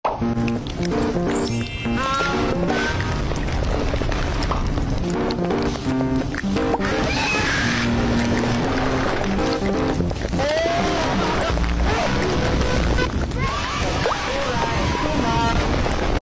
Cafe sound sound effects free download